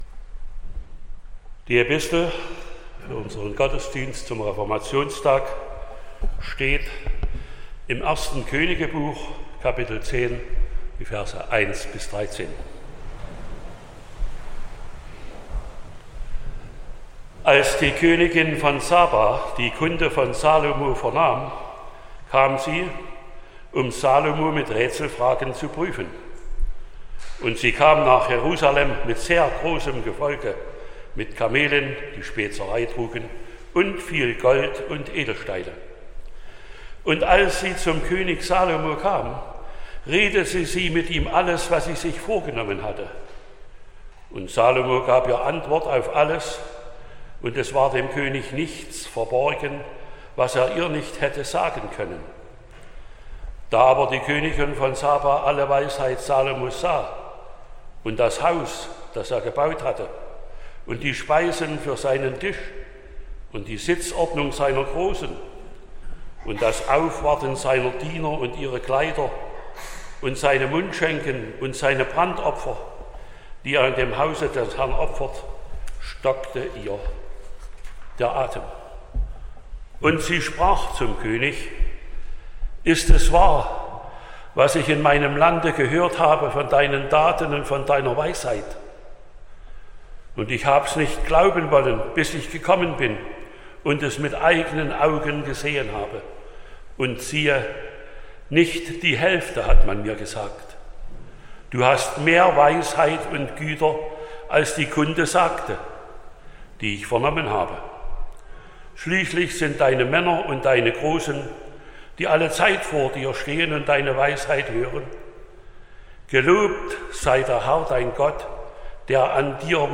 31.10.2024 – Gottesdienst
Predigt und Aufzeichnungen
Predigt (Audio): 2024-10-31_Wie_Gott_uns_selig_macht.mp3 (15,9 MB)